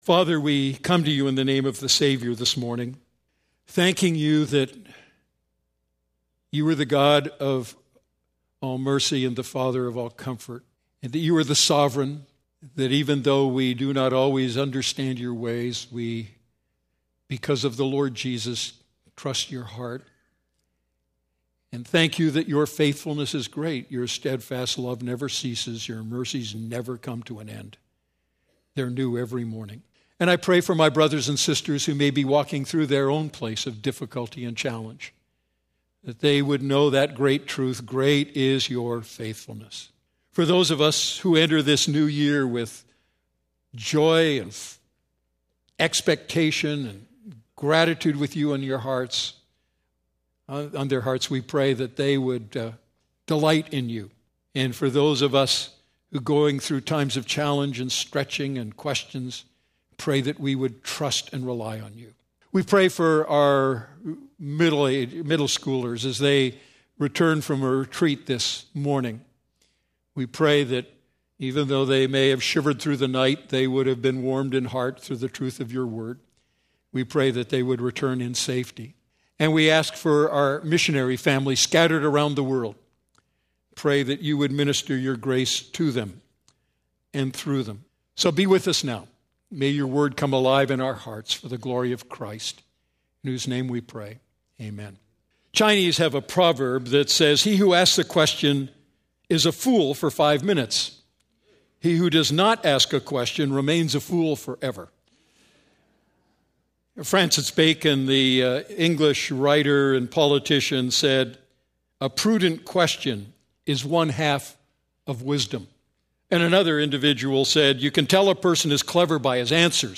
A message from the series "Great Questions?."